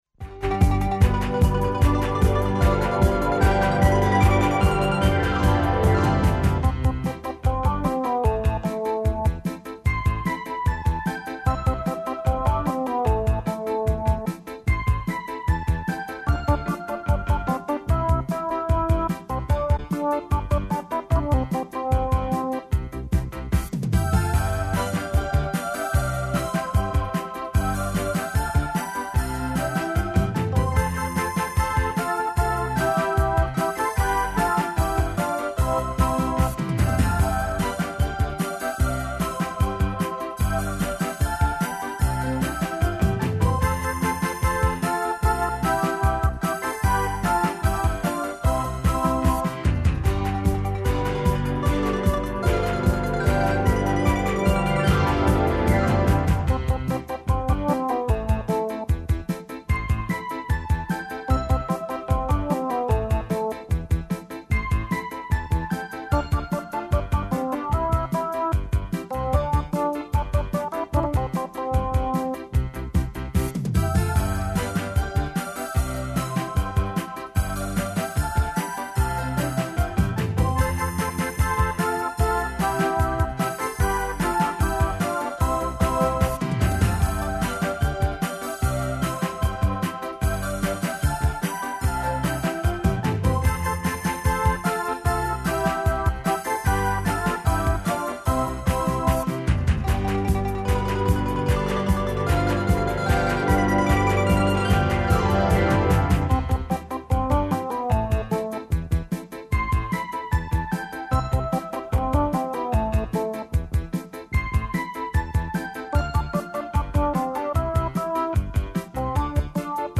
О томе говоре деца - гости данашње емисије.